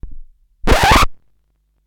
Record Scratch #6
comedy crackle effect lp needle noise phonograph record sound effect free sound royalty free Funny